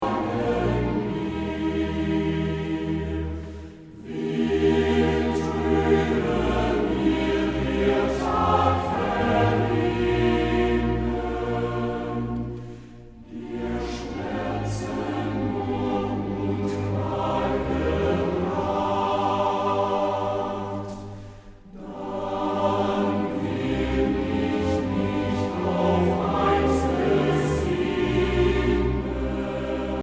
Trostvoll, harmonisch und warm